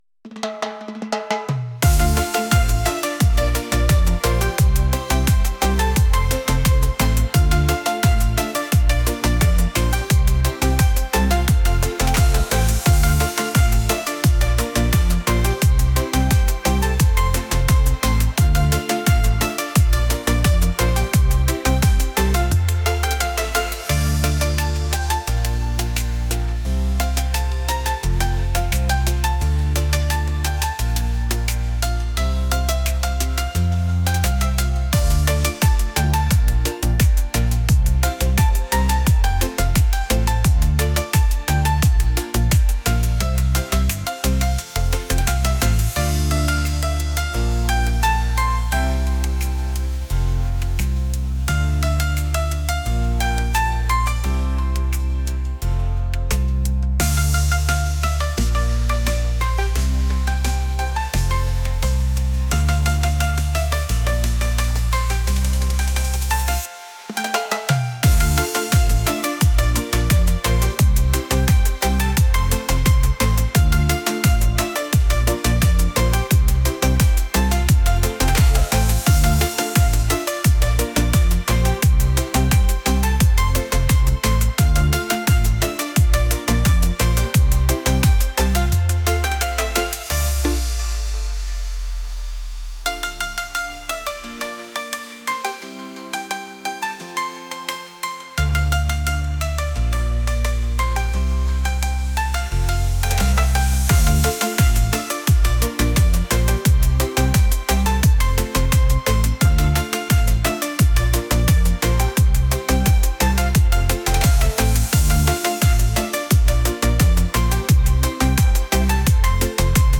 pop | romantic